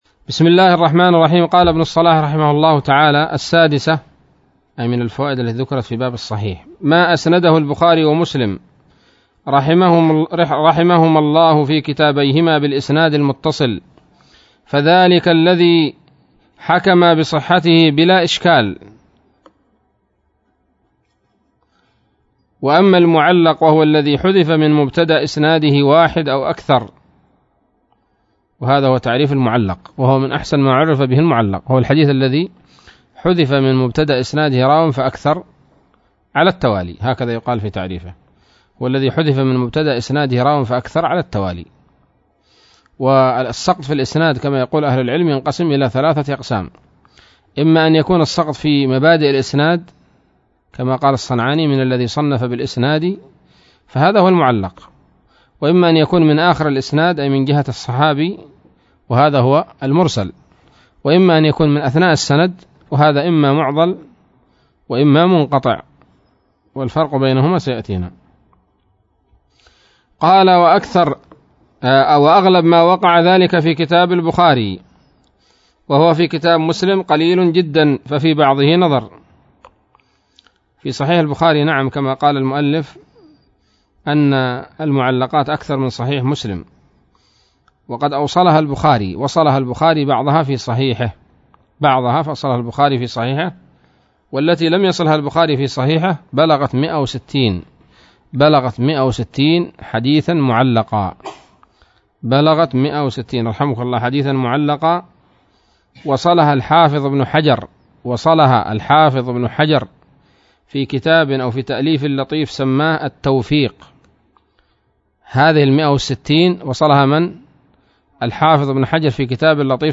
الدرس الحادي عشر من مقدمة ابن الصلاح رحمه الله تعالى